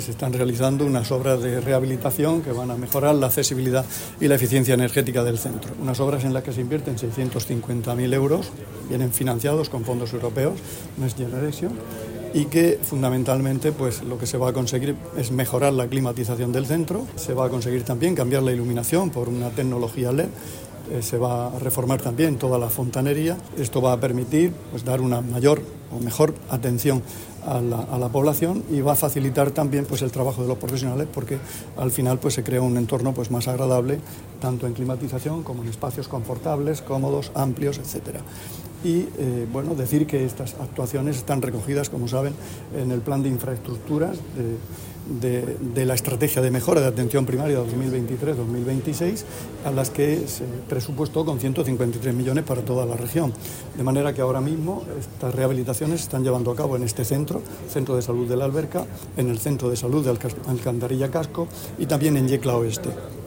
Sonido/ Declaraciones del consejero de Salud, Juan José Pedreño, en su visita a las obras del centro de salud de La Alberca.